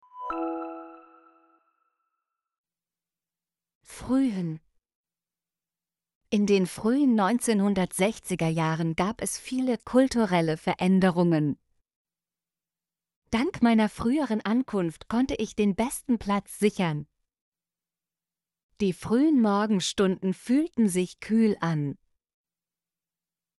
frühen - Example Sentences & Pronunciation, German Frequency List